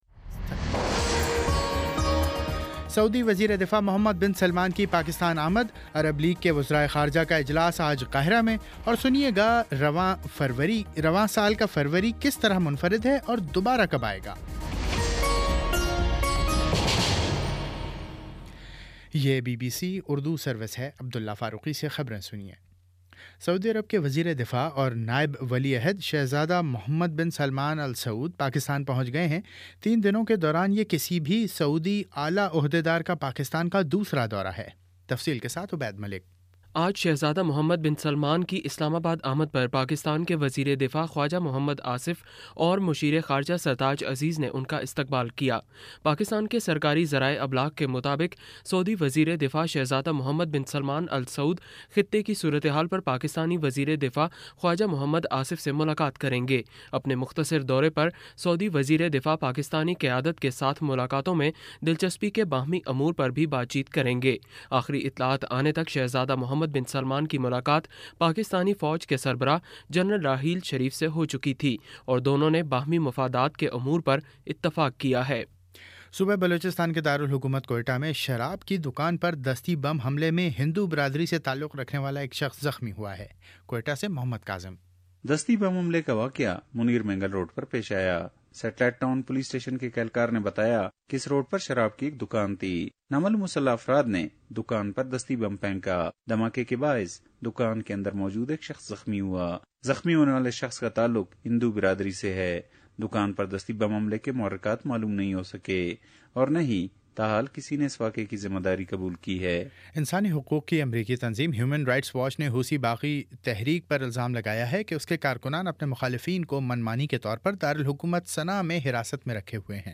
جنوری 10 : شام پانچ بجے کا نیوز بُلیٹن